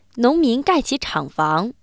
neutral